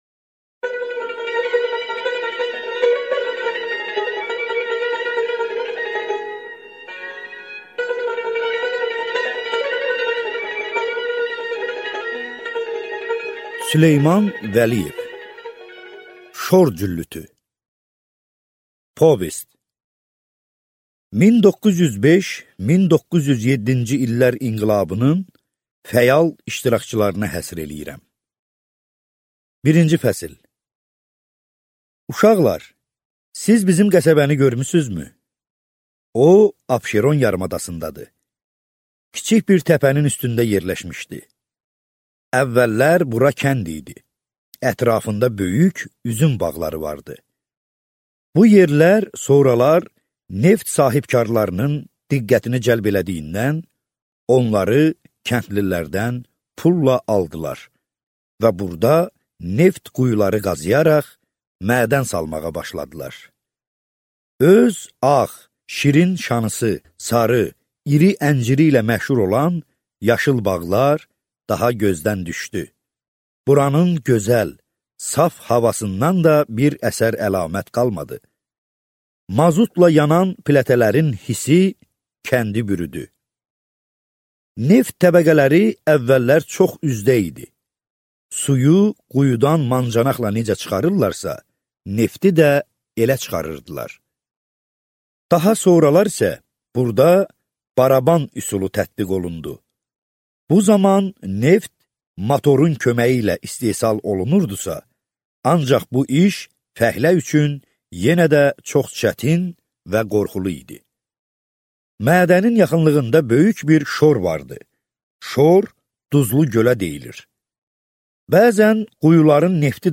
Аудиокнига Şor cüllütü | Библиотека аудиокниг